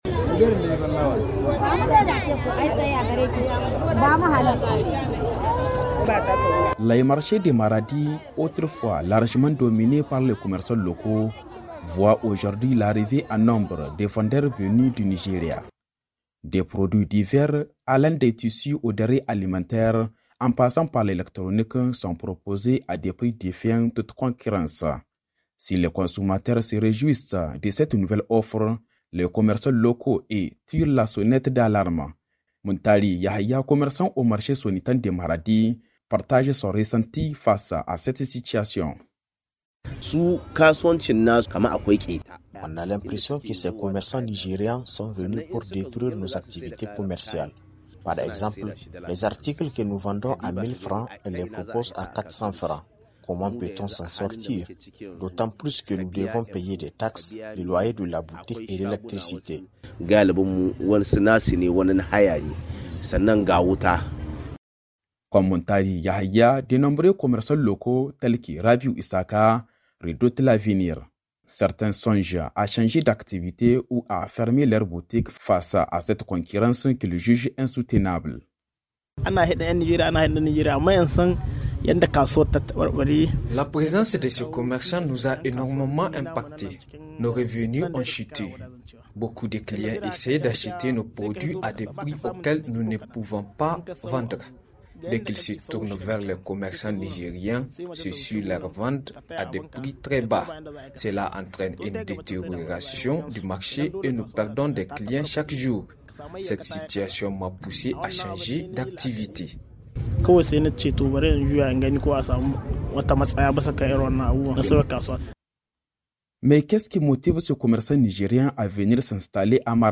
Reportage-sur-limpact-de-la-concurrence-entre-les-commercants-de-Maradi-et-ceux-du-Nigeria.Mp3.mp3